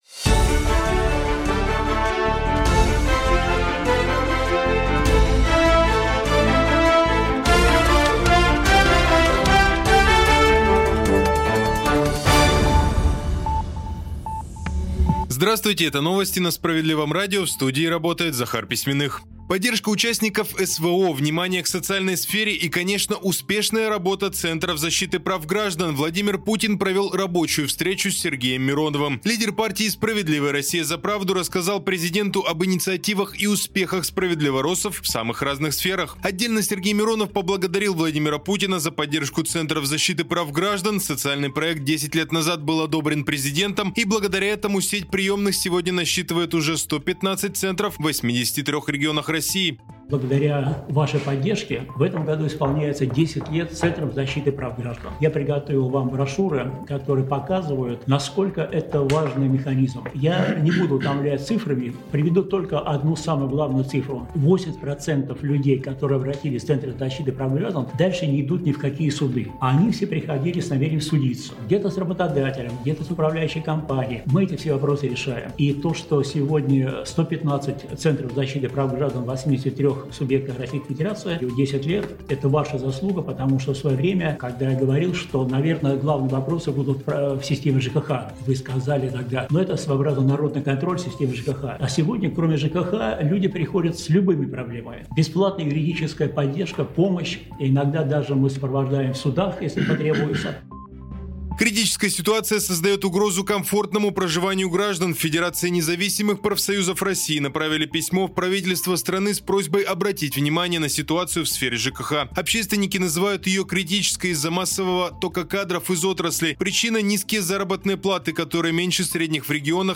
Новости